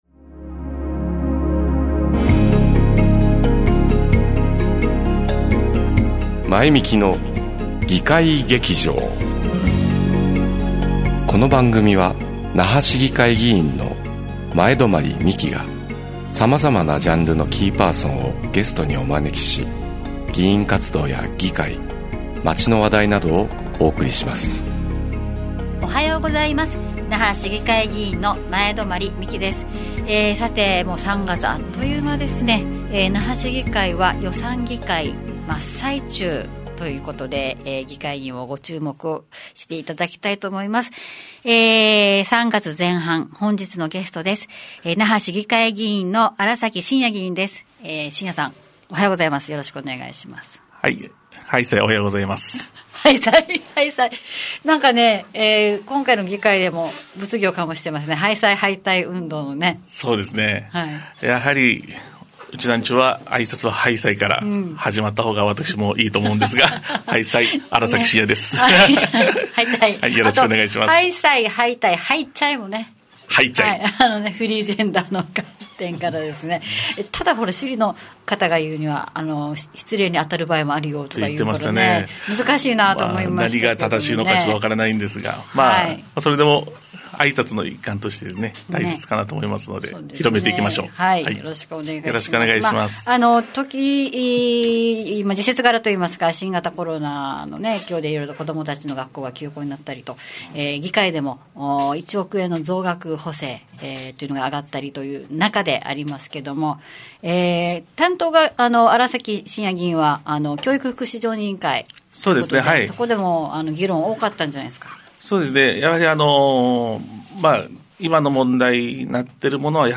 ゲスト:新崎進也/那覇市議会議員 〜 沖縄県の心臓移植医療について考える